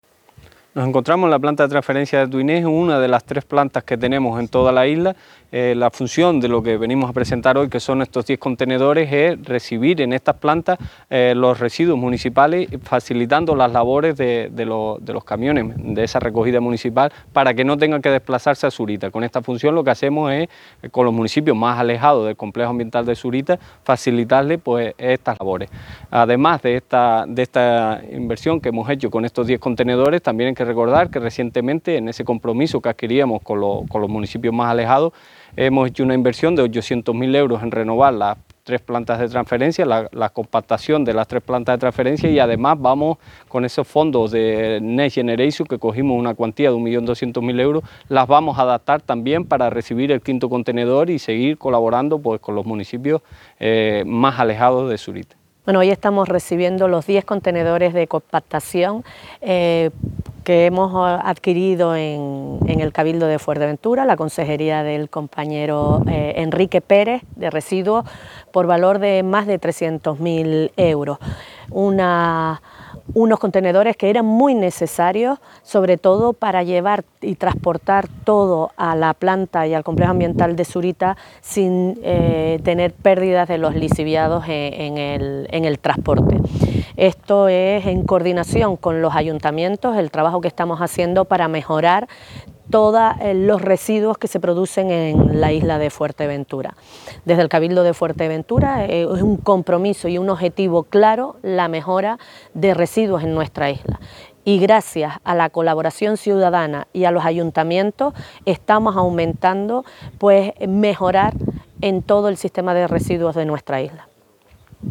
Entrevistas y declaraciones